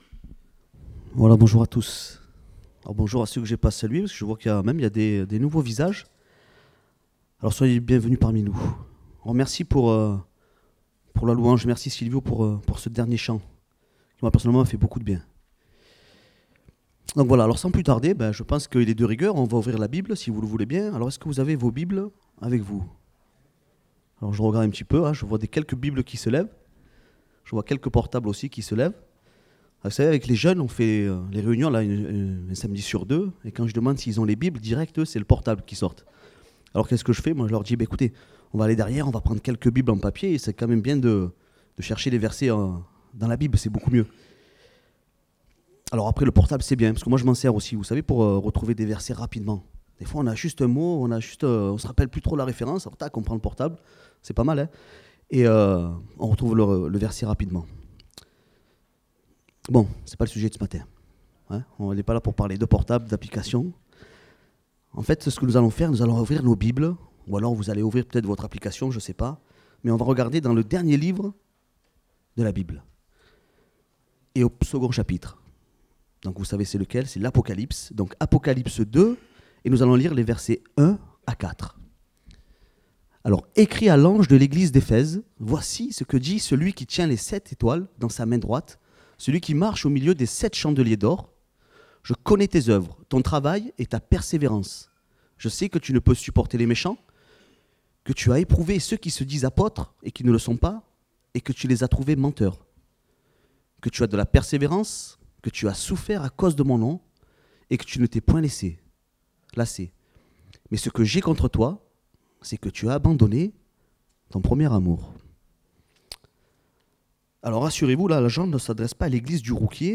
Date : 26 mai 2024 (Culte Dominical)